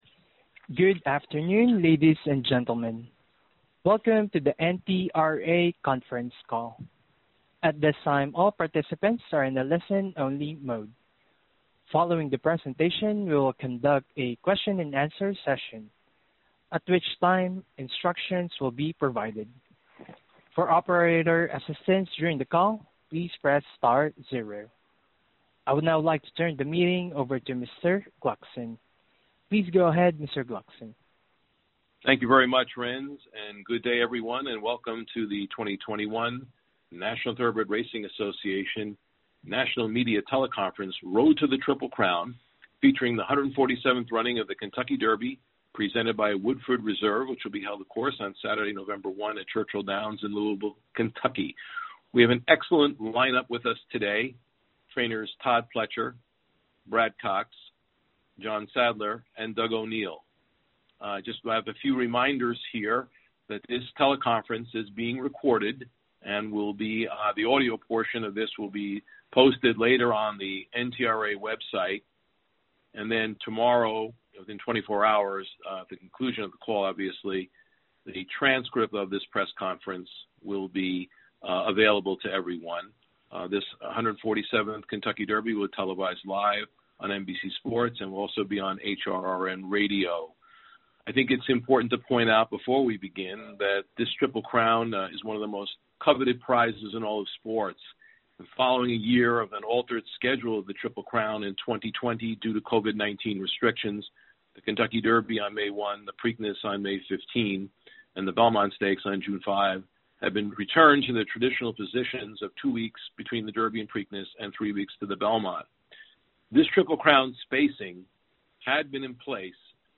National Media Teleconference